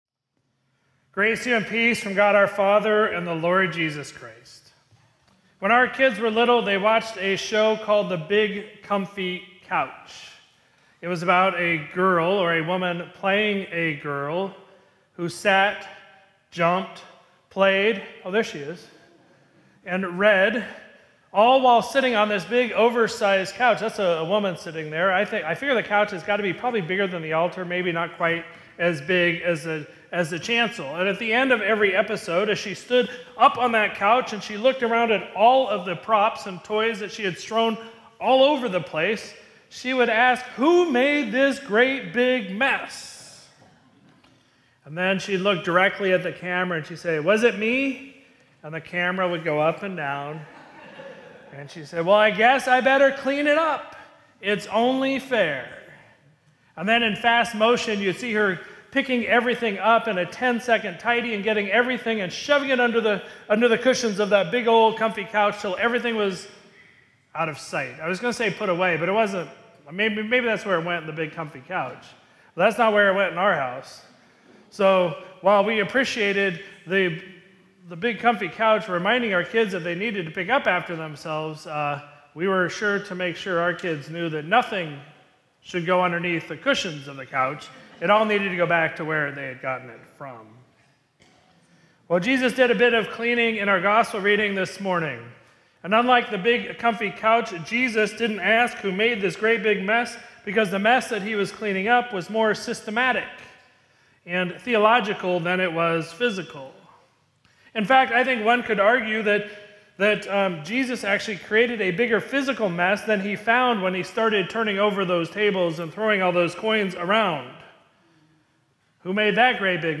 Sermon from Sunday, January 18, 2026